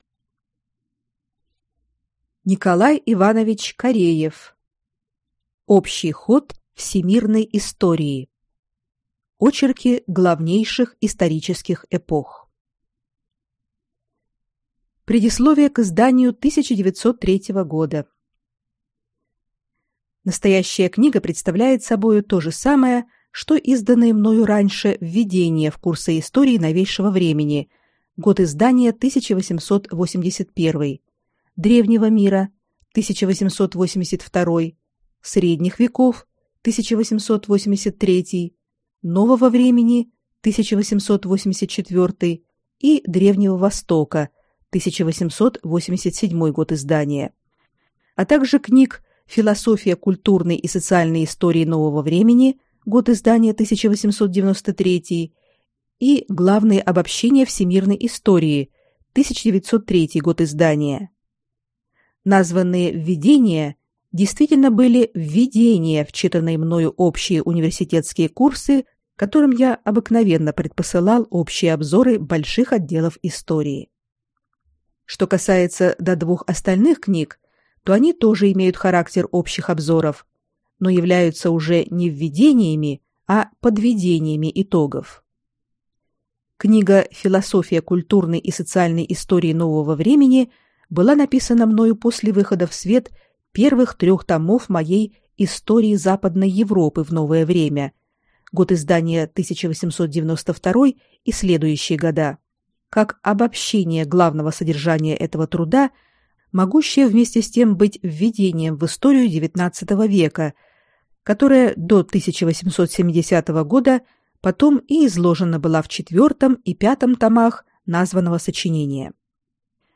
Аудиокнига Общий ход всемирной истории. Очерки главнейших исторических эпох | Библиотека аудиокниг